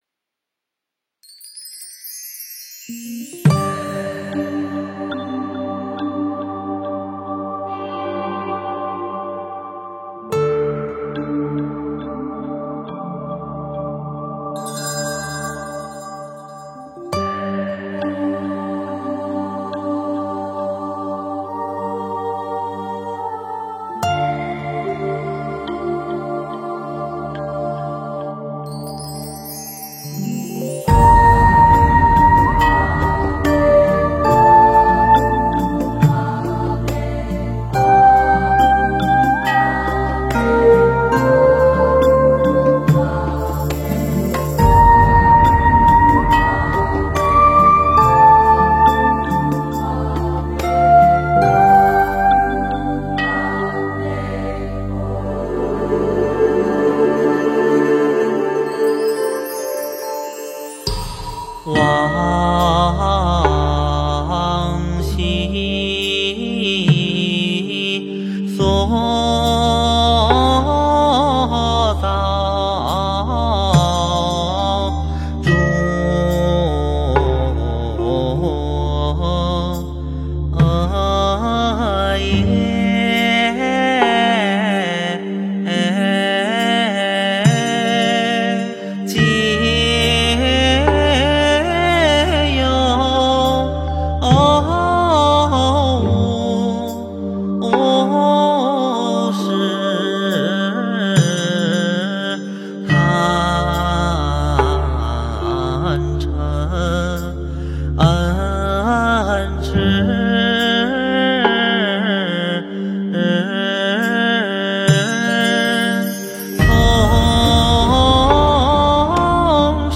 诵经
佛音 诵经 佛教音乐 返回列表 上一篇： 授记语 下一篇： 宗喀巴祈请颂 相关文章 貧僧有話17說：神明朝山联谊会--释星云 貧僧有話17說：神明朝山联谊会--释星云...